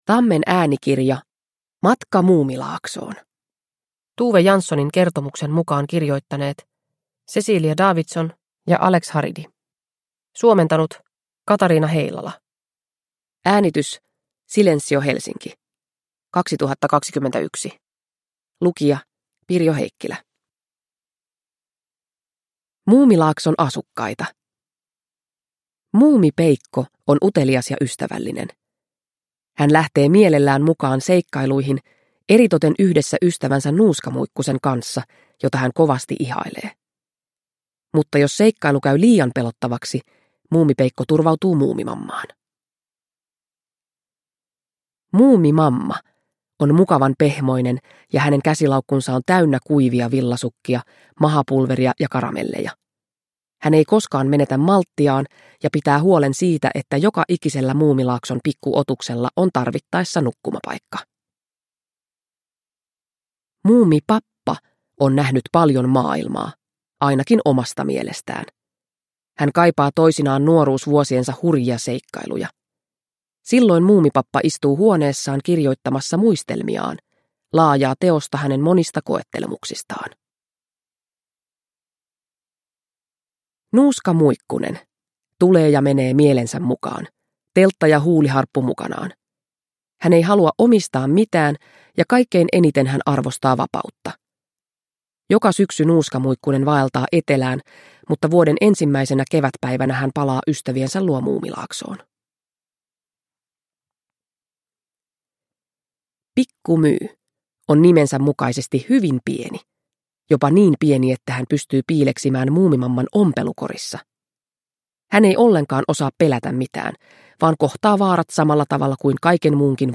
Matka Muumilaaksoon – Ljudbok